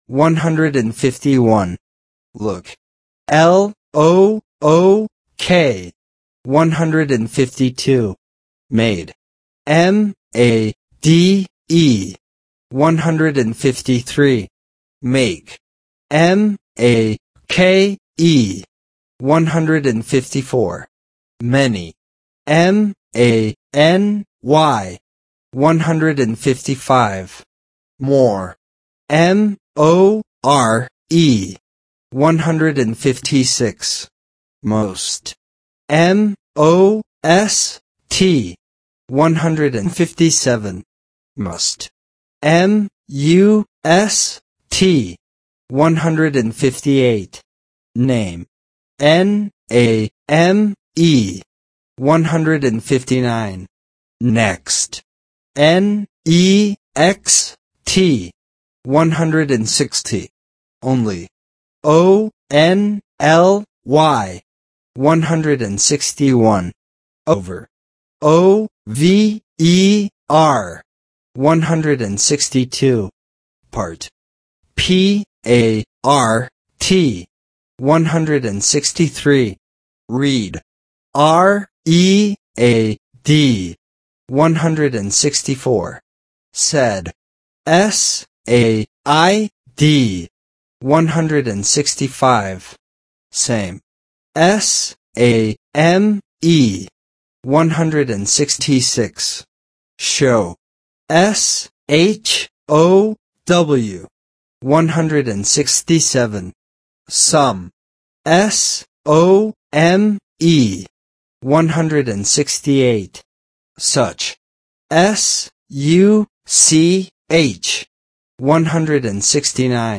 Spelling Exercises